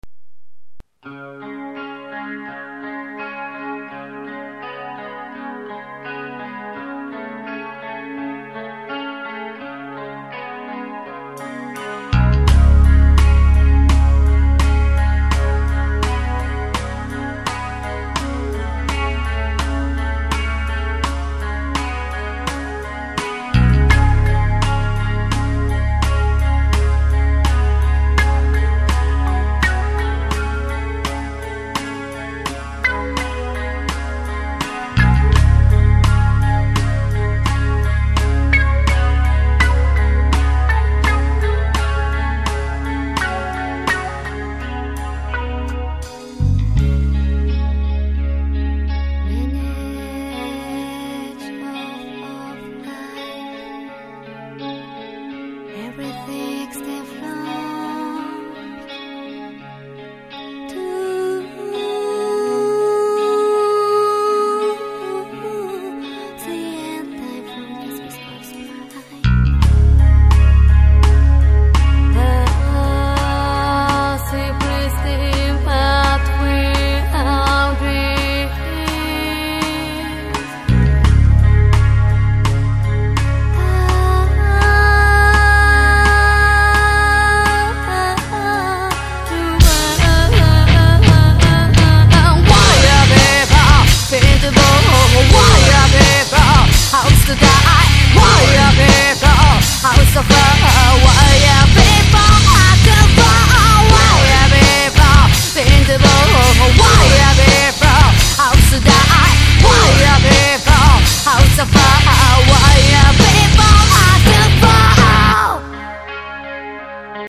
панк-рок группа